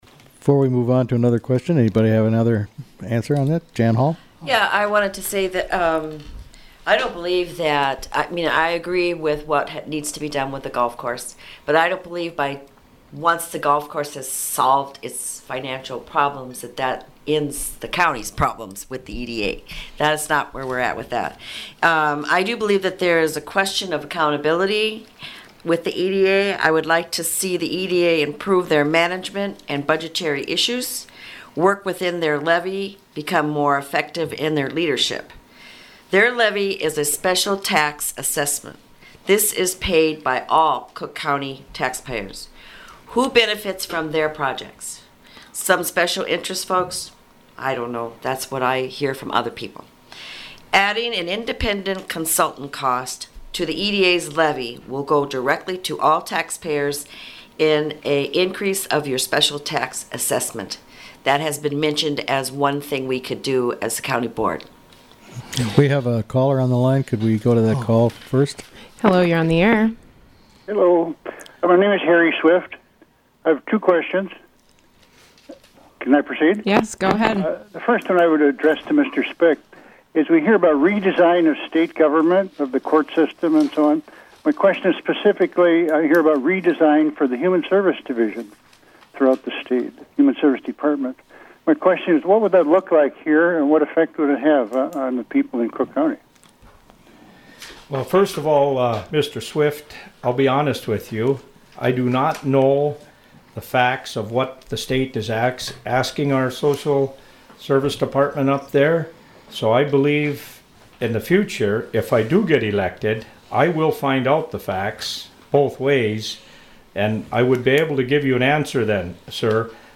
Attachment Size PartTwoCountyForum.mp3 41.05 MB PART TWO: Candidates for Cook County Commissioner gathered in the WTIP studio Tuesday, Oct. 12 to answer questions and take listener calls.